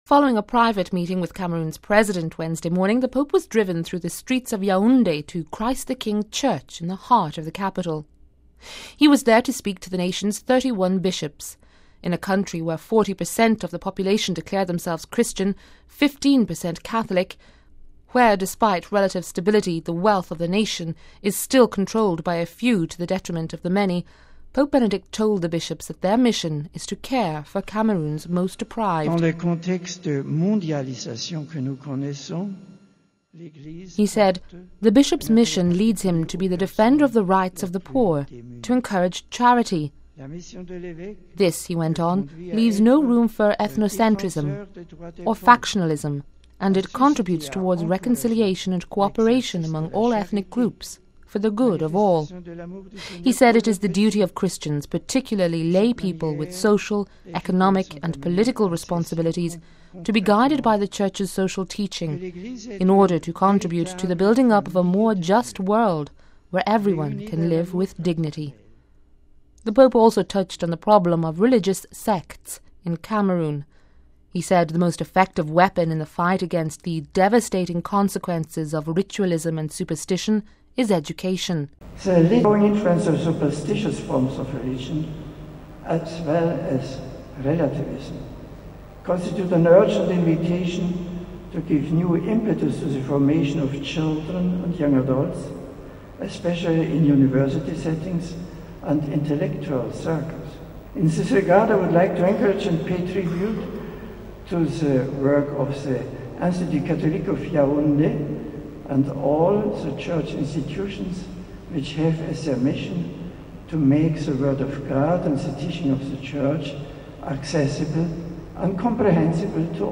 We have this report: RealAudio